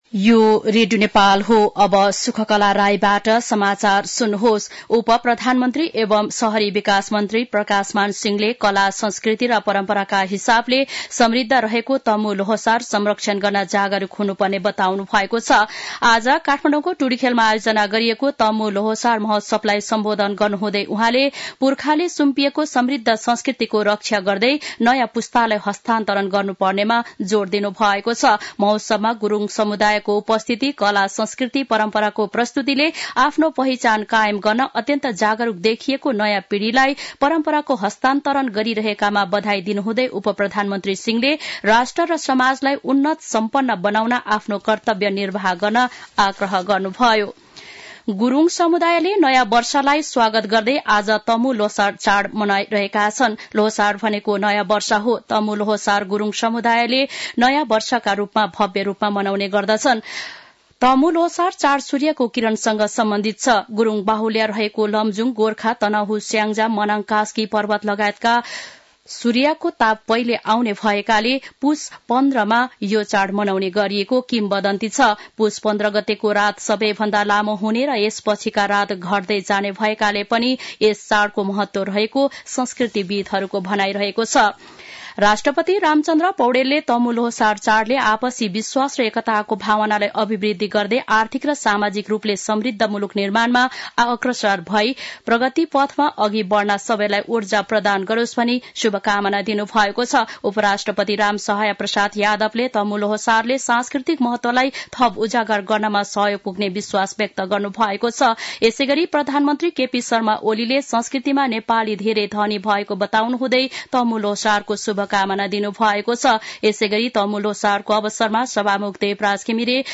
साँझ ५ बजेको नेपाली समाचार : १६ पुष , २०८१